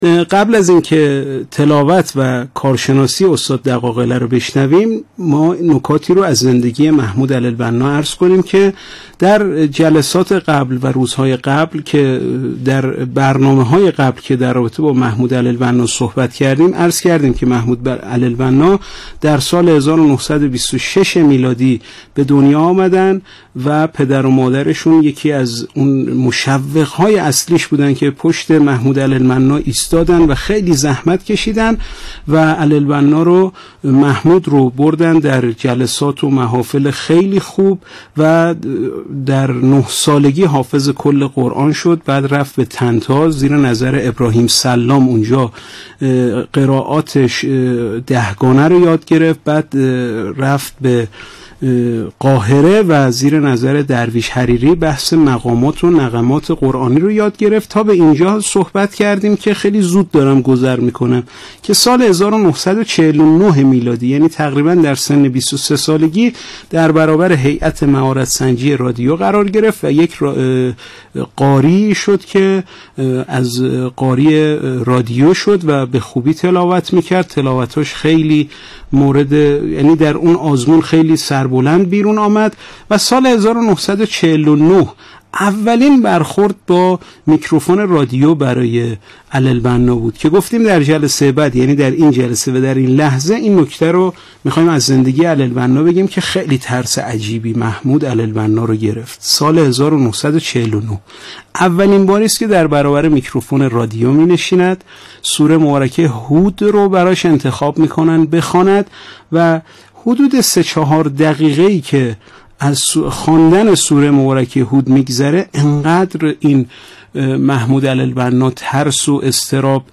تلاوت آیات 40 تا 47 سوره مبارکه احزاب را به مدت 6 دقیقه می‌شنوید.